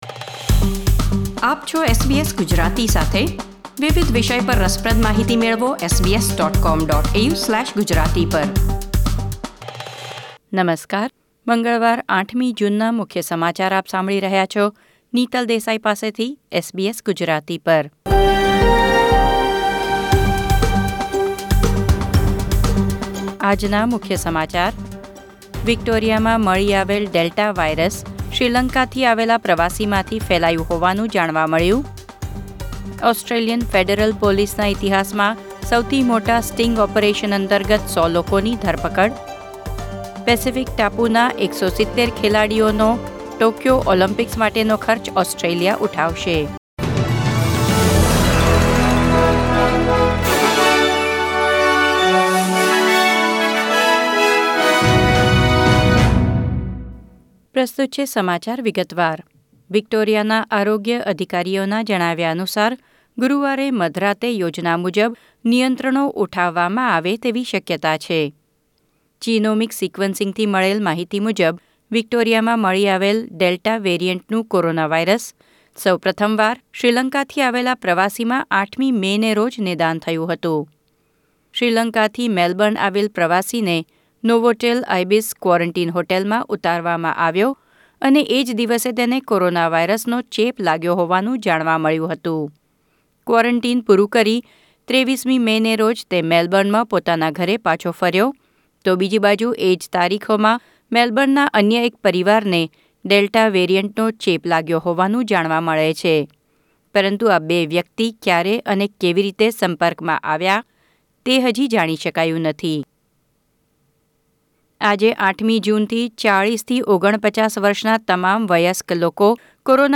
SBS Gujarati News Bulletin 8 June 2021